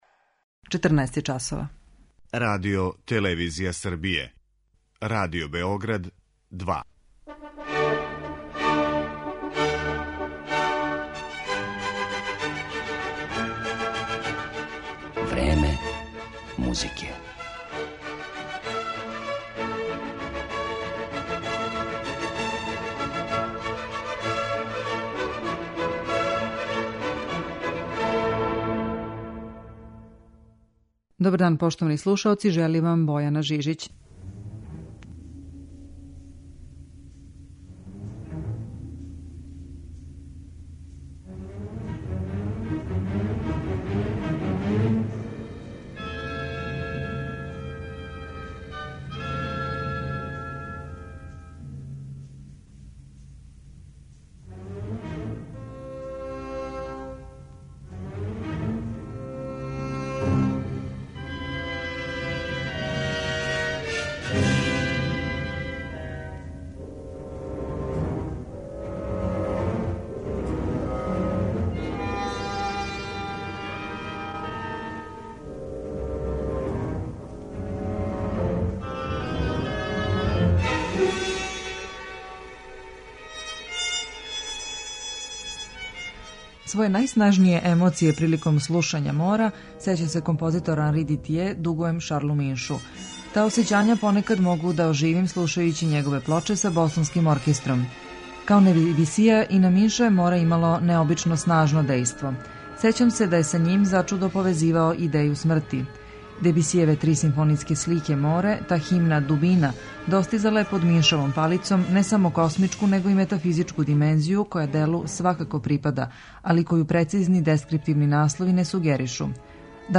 Овог великог музичара, рођеног у Алзасу 1891. године, историја памти првенствено као врхунског тумача француске оркестарске музике. Представићемо га претежно у овом репертоару и у архивским снимцима Радио Београда.